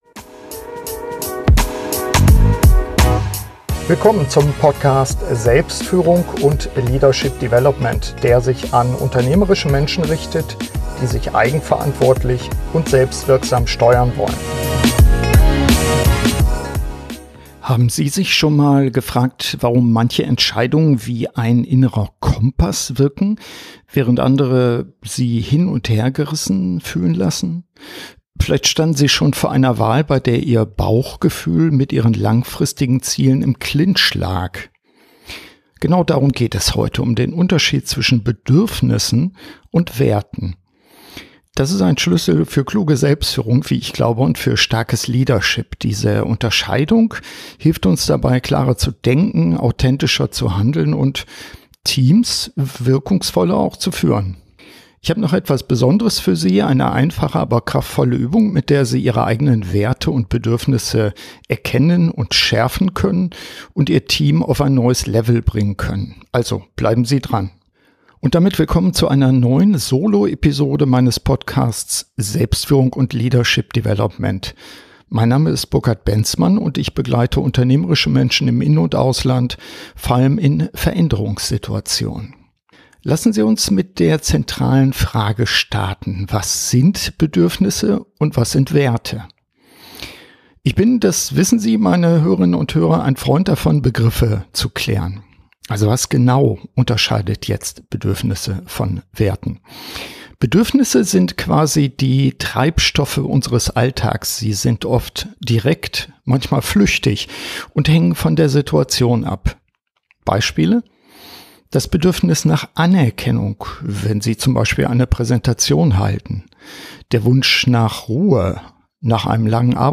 In dieser Solo-Episode geht es um einen Schlüssel zur Selbstführung: den Unterschied zwischen Bedürfnissen und Werten. Sie erfahren, warum diese Unterscheidung essenziell für wirksame Entscheidungen ist – im Alltag wie in der Führung.